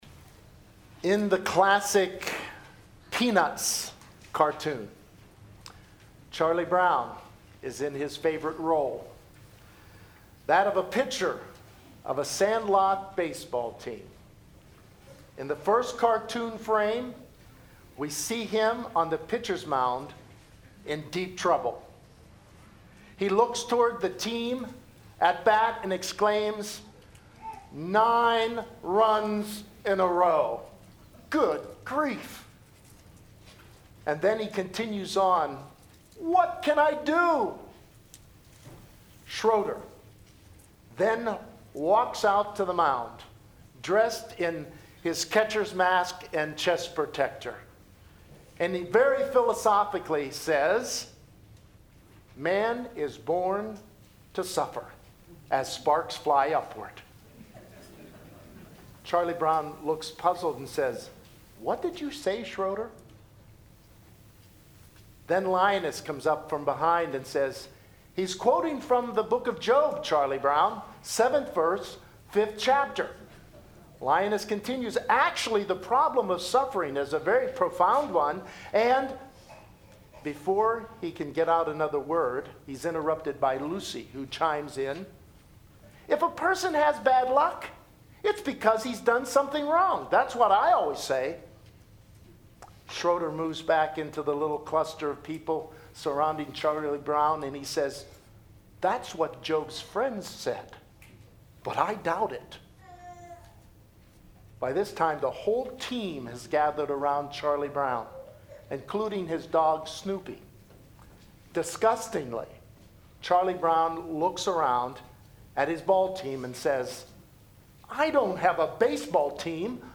Faith Bible Fellowship Church - Tallmadge, Ohio Sermons: Job Series